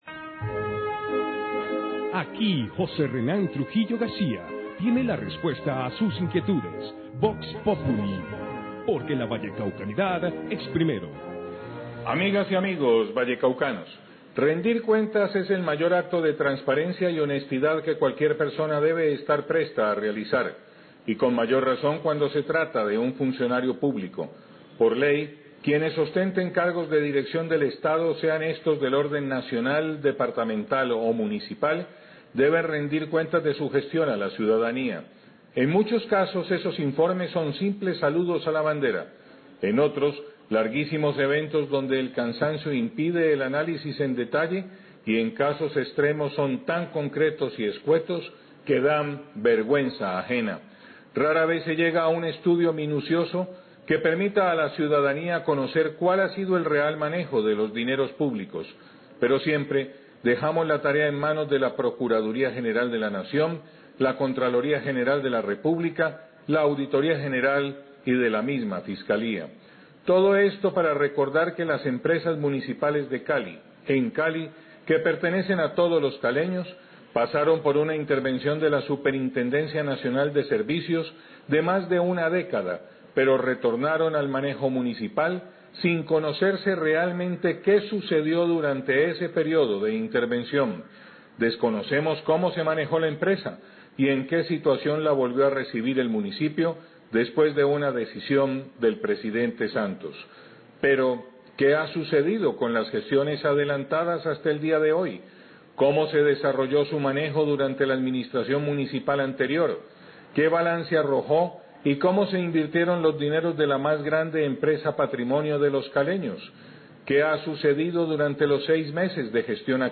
SECCIÓN ‘VOX POPULI SOBRE EMCALI’, 7-37AM
Radio